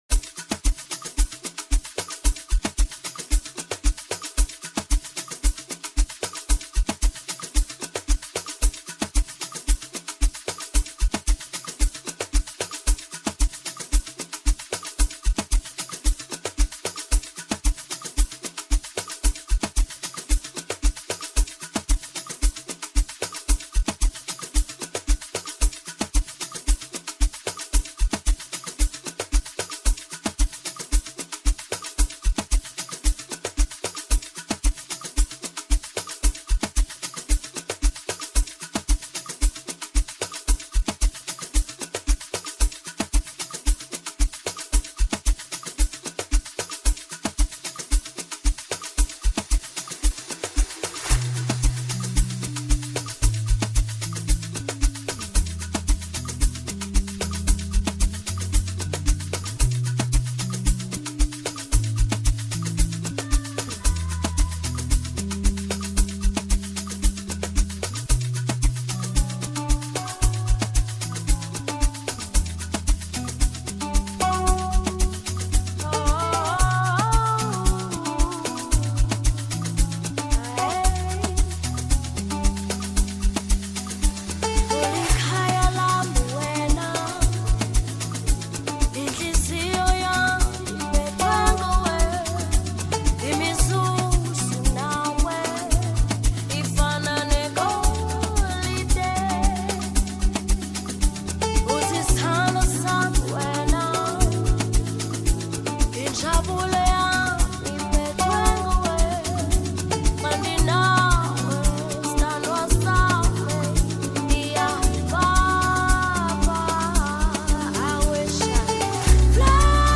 powerful and emotive voice
delivered with passion and emotion